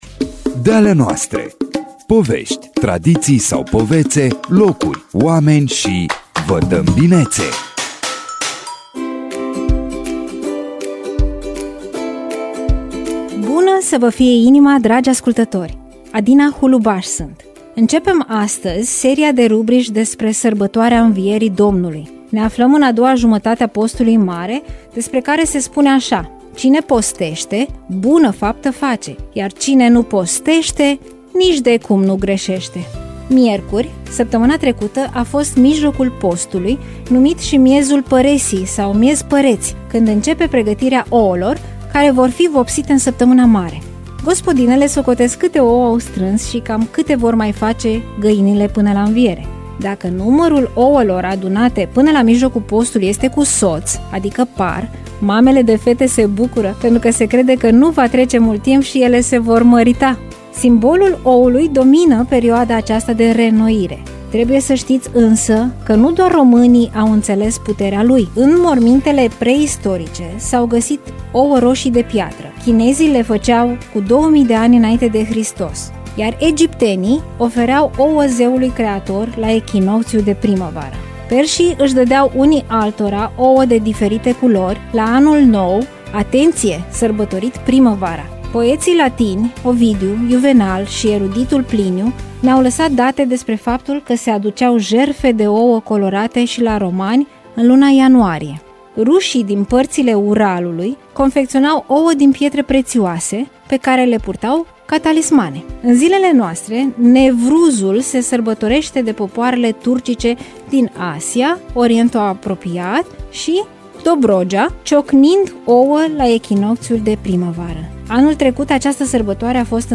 Am aflat astăzi, la radio